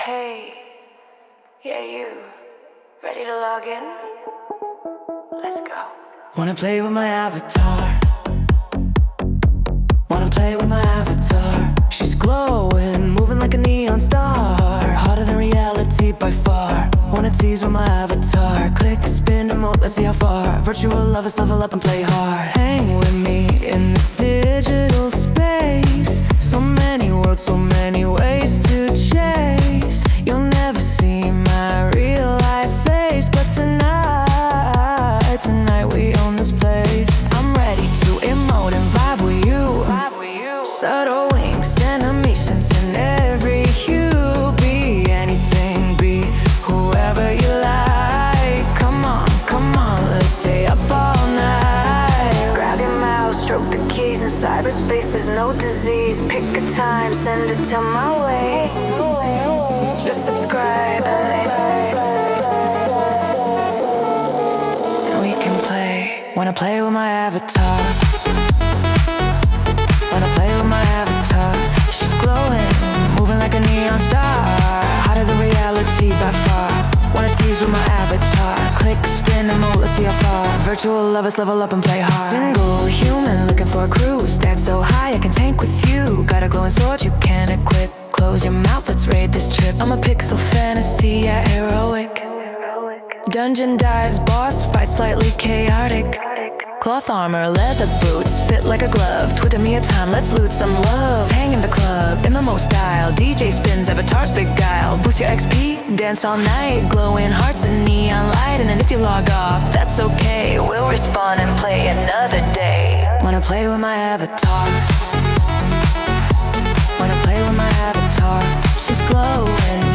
faster club beat and vibe to it